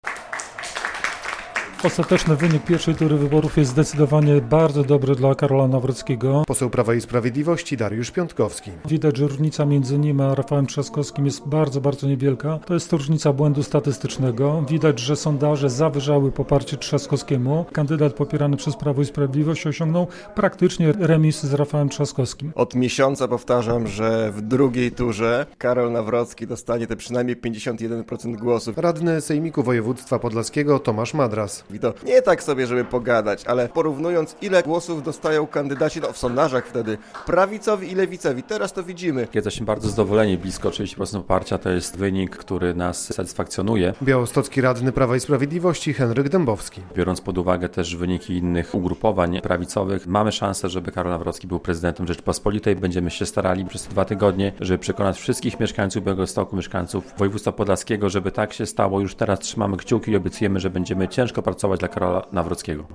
Co sądzą o pierwszych, sondażowych wynikach wyborów podlascy politycy Prawa i Sprawiedliwości? - relacja